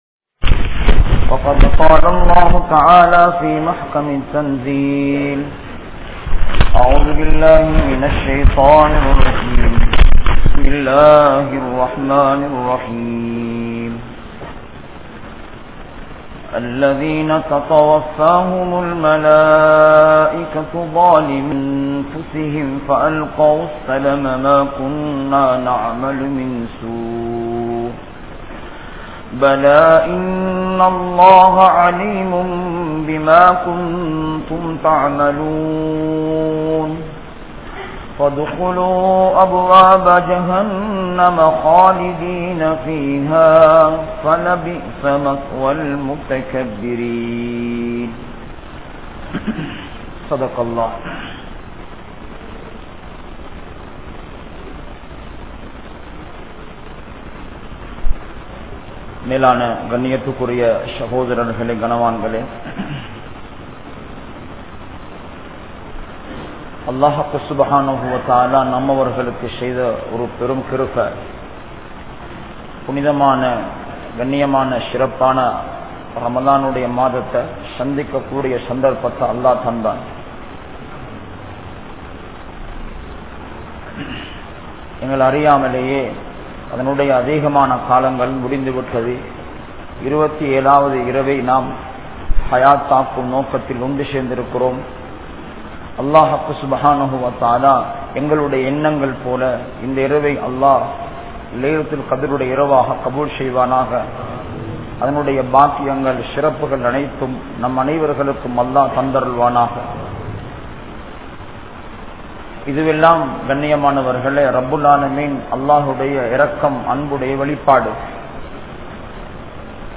Nadikkum Manitharhal | Audio Bayans | All Ceylon Muslim Youth Community | Addalaichenai